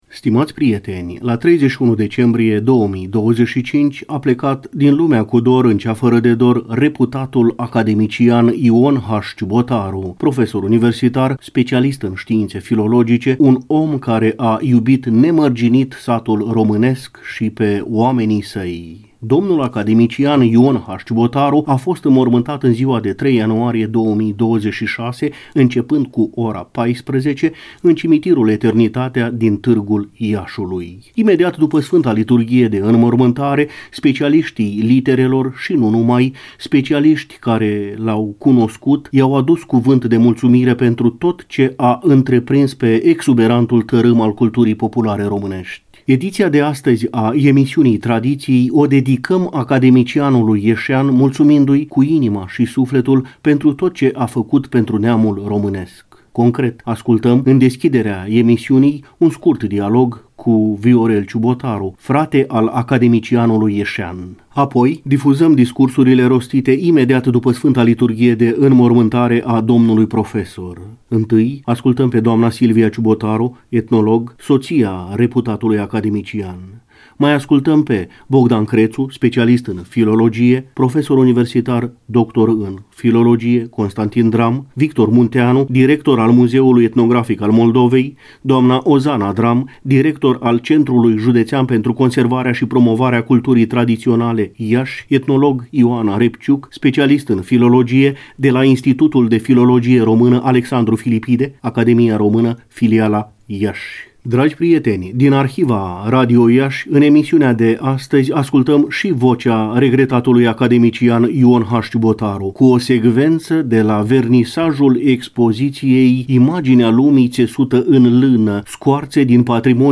Apoi, difuzăm discursurile rostite imediat după sfânta liturghie de înmormântare a domnului profesor.